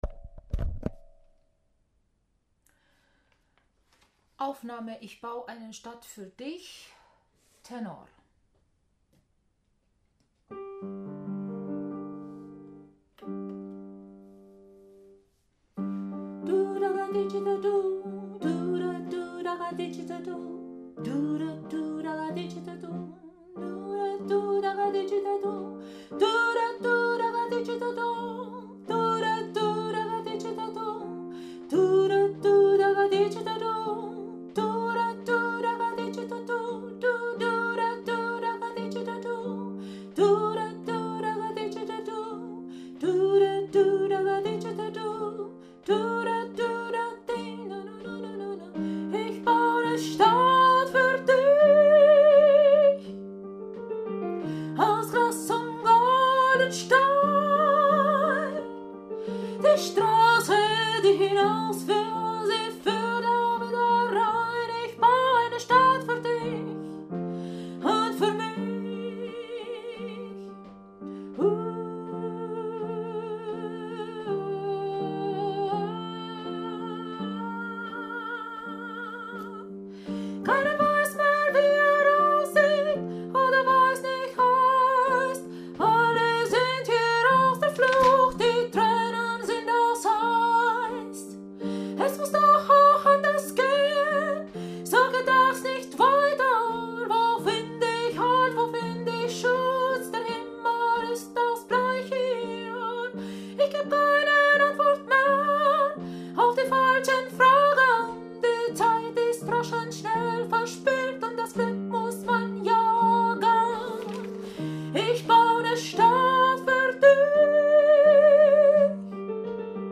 Stadt für dich – Tenor
Stadt-für-Dich-Tenor.mp3